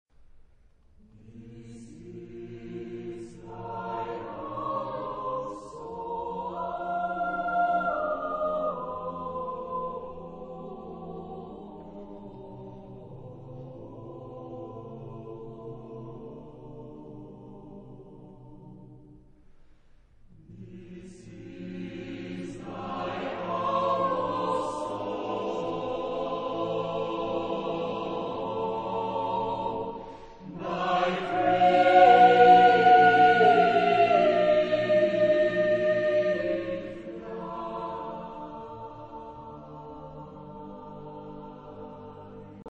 Genre-Style-Forme : Motet ; Profane
Type de choeur : SSAATBB  (7 voix mixtes )
Solistes : Alt (1) / Bass (1)  (2 soliste(s))
Tonalité : libre